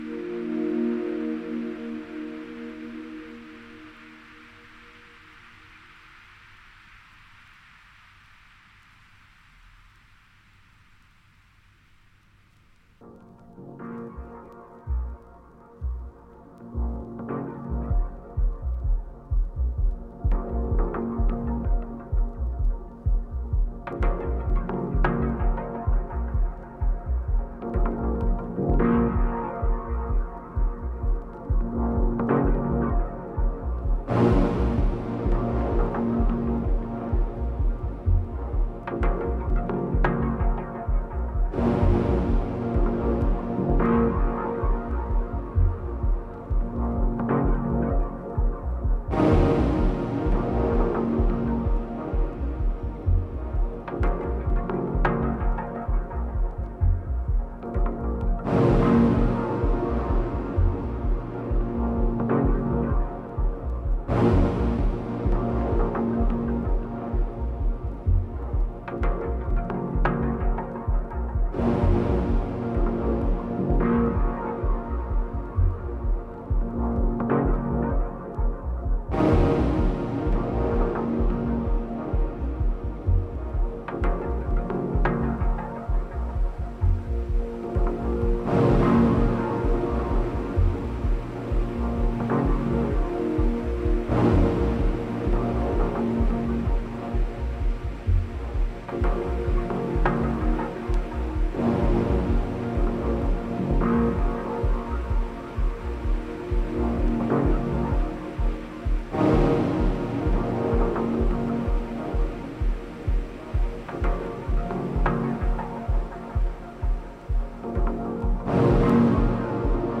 Dub Techno Techno